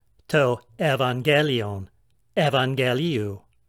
Lexical Form: τὸ εὐαγγέλιον, εὐαγγελίου <